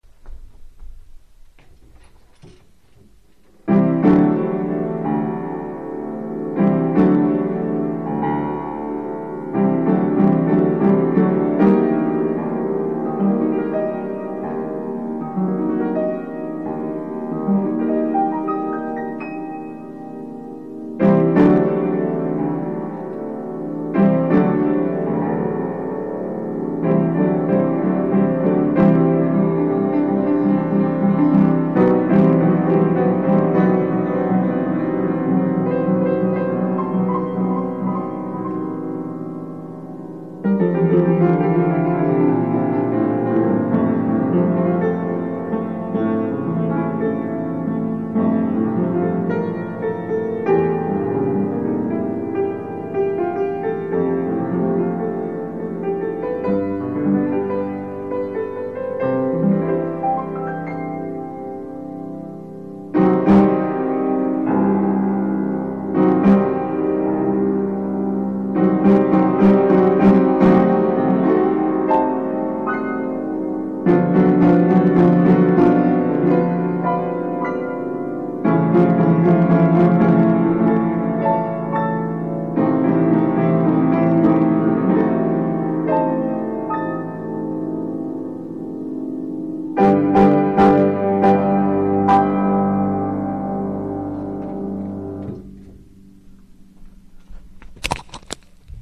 בהתחלה אכן זה נשמע כך - אבל בשלב כלשהו נשמע פתאום ההיפך!
בהתחלה הוא כועס אח"כ הוא נרגע..
אכן כועס... לדעתי לפחות...